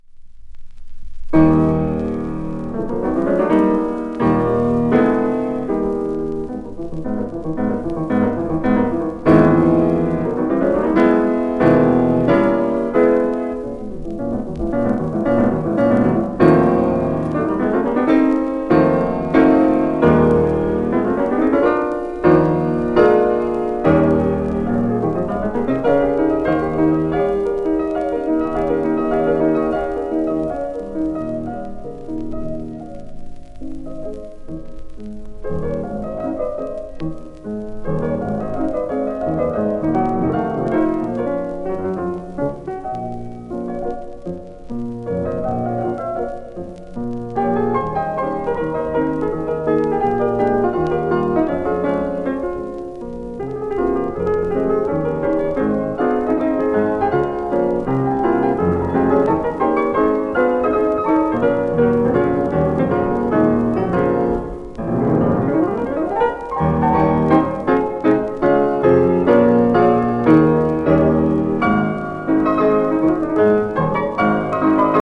1952年録音 サンプル盤？
ユダヤ系、ベルギーのピアニストで教師。アルゲリッチや内田光子の師としても知られる